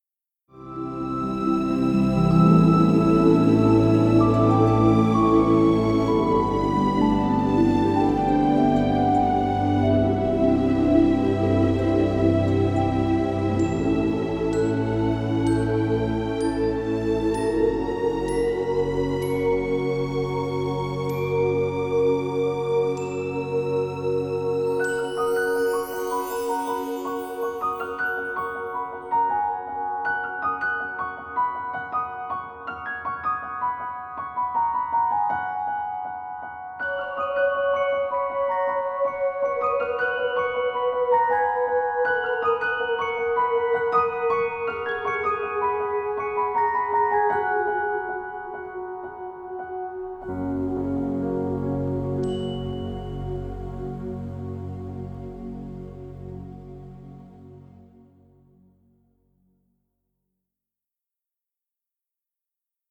sensitive and gentle score
gentle transparency and full orchestra-like ambience